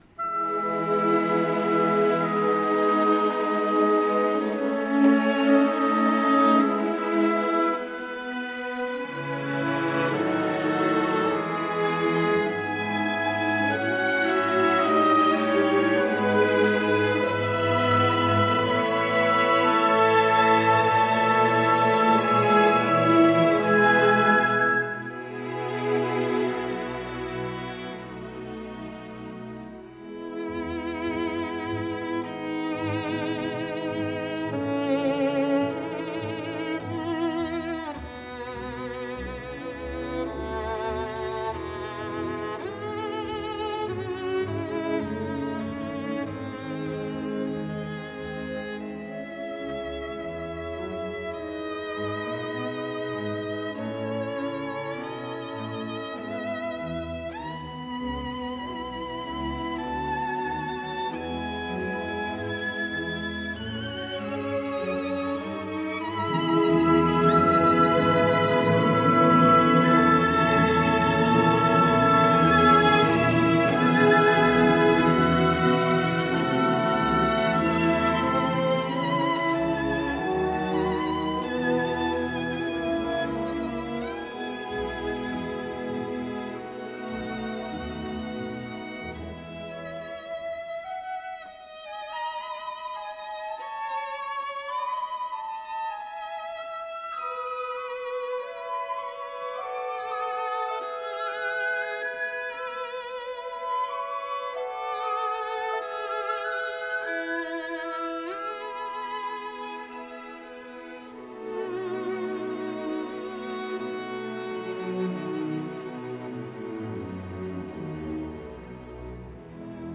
Ascultă “Balada pentru vioară și Orchestră”, compusă de George Enescu, în interpretarea unică a faimosului violonist David Garrett, împreună cu Filarmonica din Monte Carlo și sub bagheta regretatului Gianluigi Gelmetti, răspunde la întrebarea din formular și poți câștiga o invitație dublă la unul din concertele festivalului dedicat marelui compozitor.
G-Enescu-Balada-pt-vioara-si-orch-David-Garrett.mp3